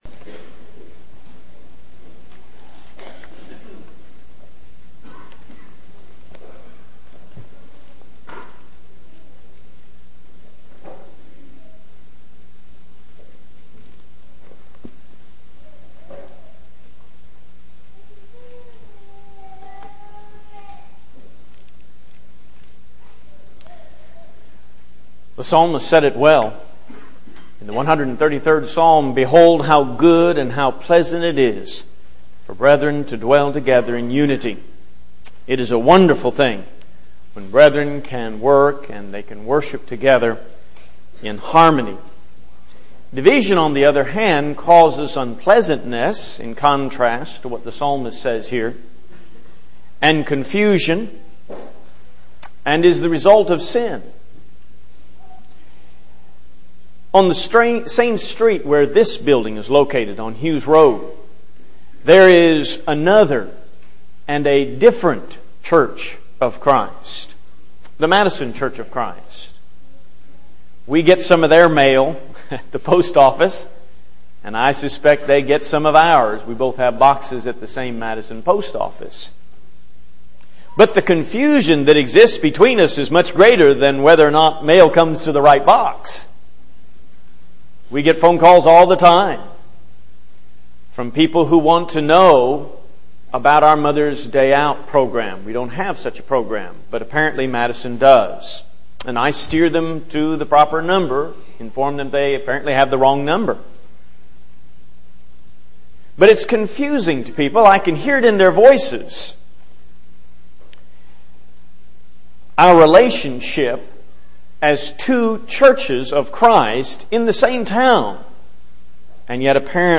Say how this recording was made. N/A Service: Sun AM Type: Sermon